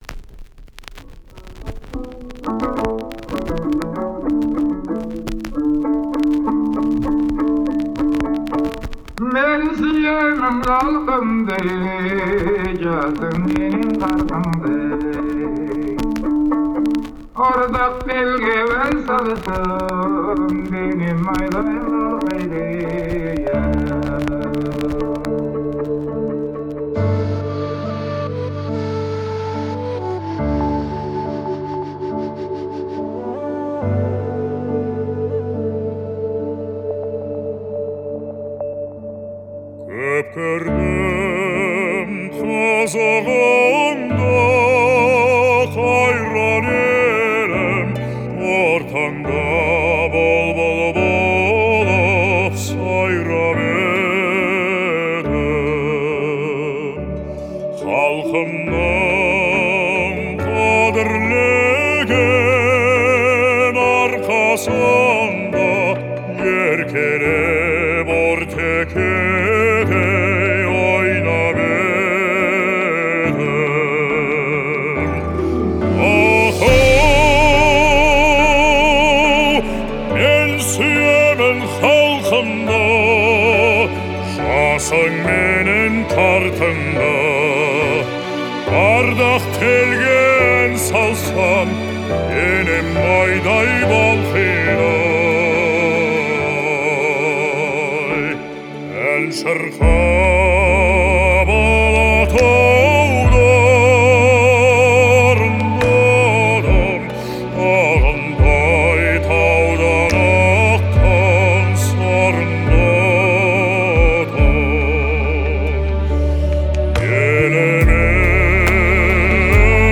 Звучание песни отличается мелодичностью и искренностью